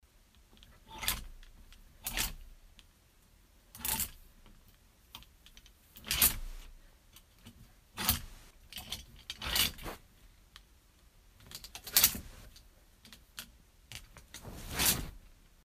12. Выбор вещей на вешалке
vybor-veshei-na-veshalke.mp3